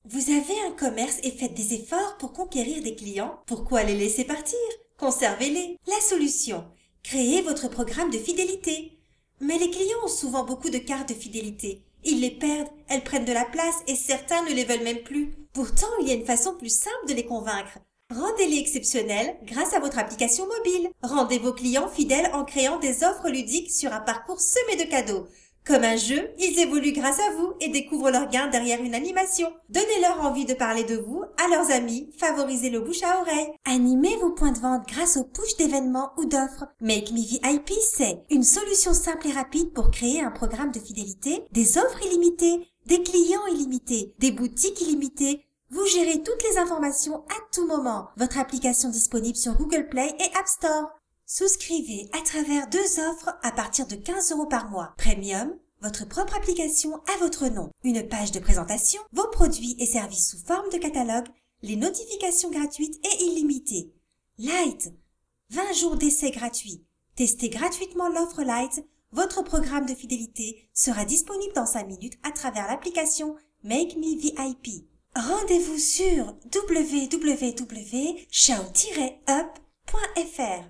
Voix off française féminine institutionnelle, calme pour des spots publicitaires ou narration.
Sprechprobe: Werbung (Muttersprache):
French voice over artist with a naturally warm, articulate and engaging voice, specializing in audio books, childrens narration.
I work from my home studio and can normally deliver within 24 hours.